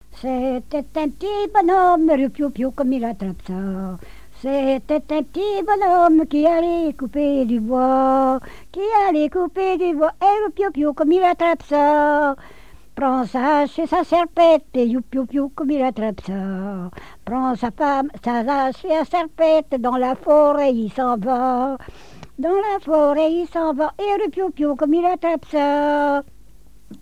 Mémoires et Patrimoines vivants - RaddO est une base de données d'archives iconographiques et sonores.
Genre laisse
collecte en Vendée
répertoire de chansons, et d'airs à danser